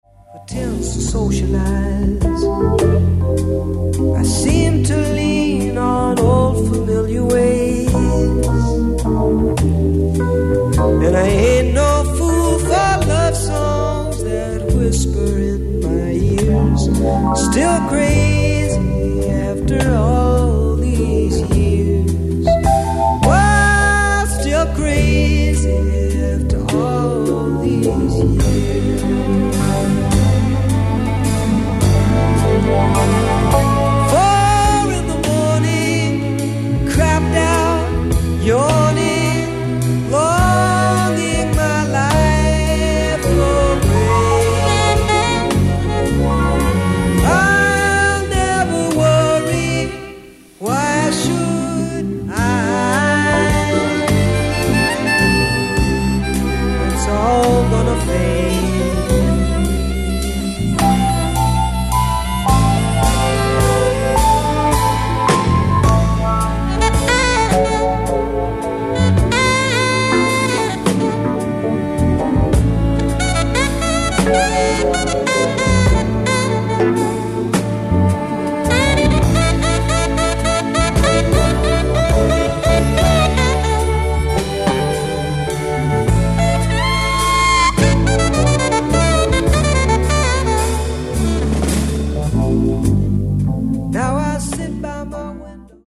ライブ・アット・カルチャーパレス、テルアビブ、イスラエル 07/05/1978
※試聴用に実際より音質を落としています。